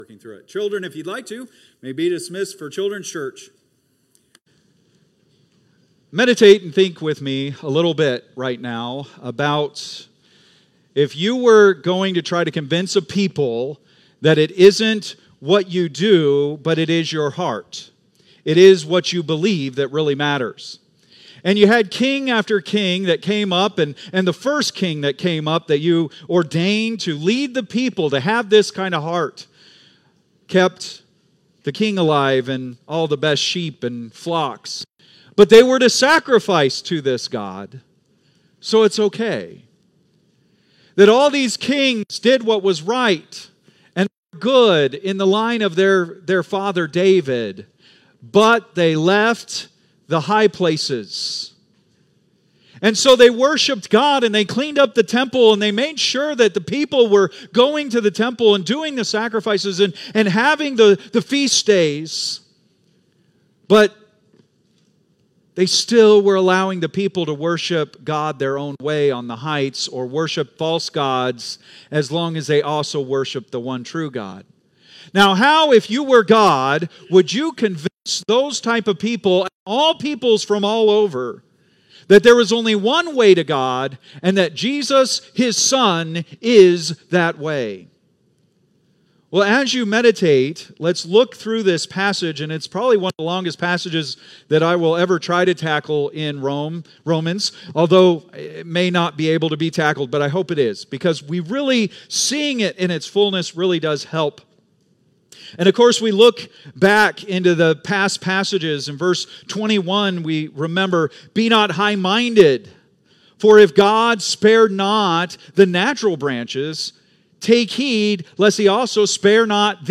Date: May 4, 2025 (Sunday Morning)